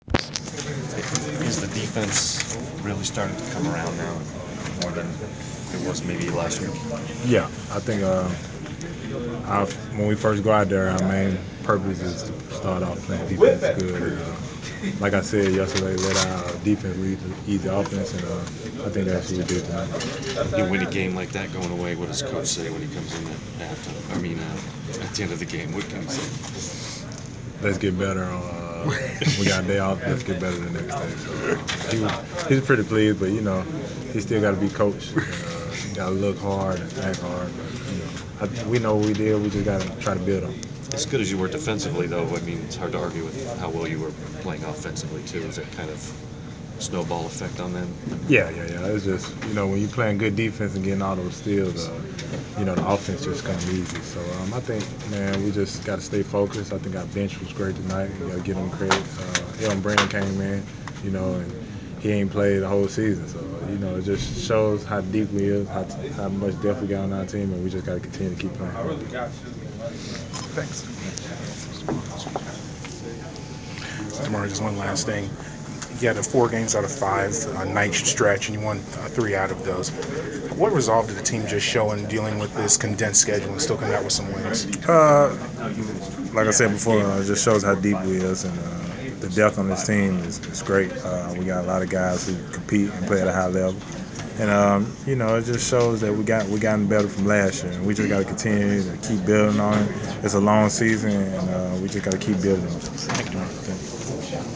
Inside the Inquirer: Post-game interview with Atlanta Hawk DeMarre Carroll (11/29/14)